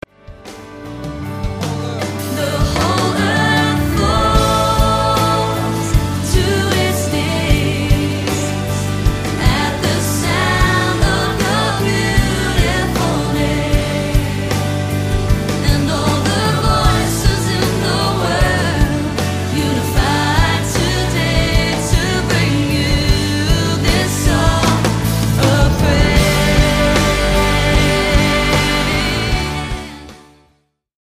Categoria: Louvor e Adoração
Bom ritmo!
Bom ambiente!